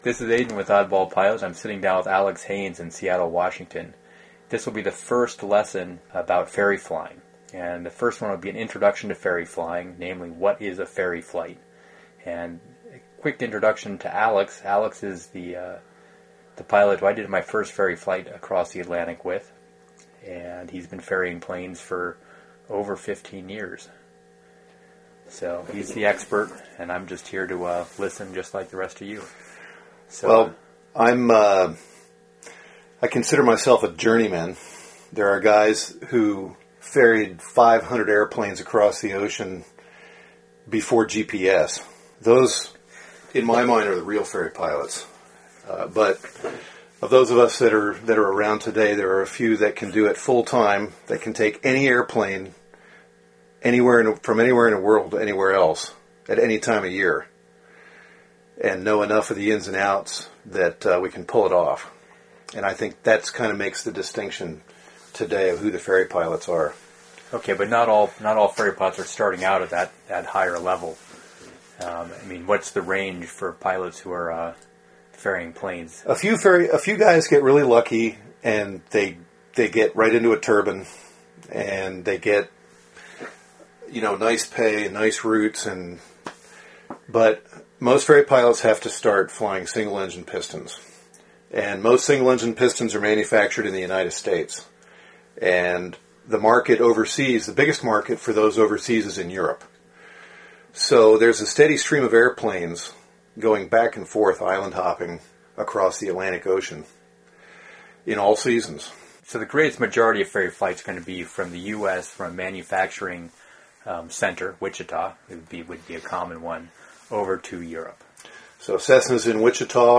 ferry_flying_course_lesson_01.mp3